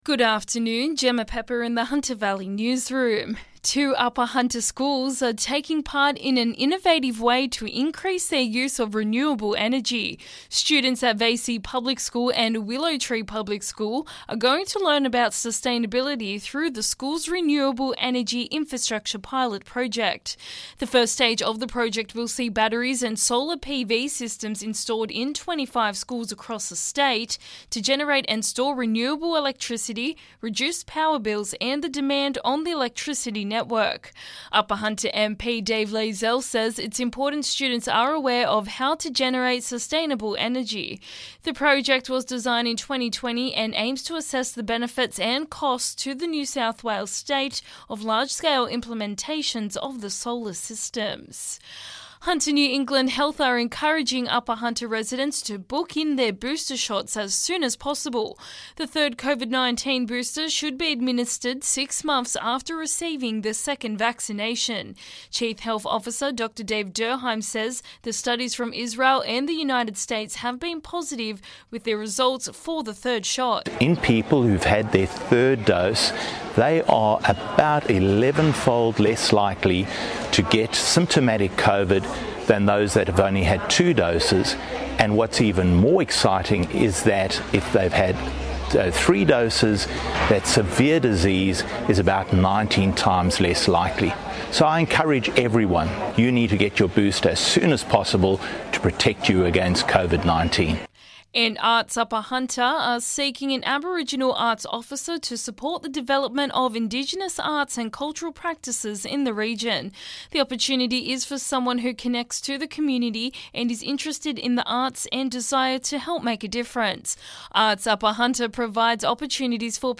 LISTEN: Hunter Valley Local News Headlines 1/11/2021